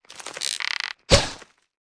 attakc_act_1.wav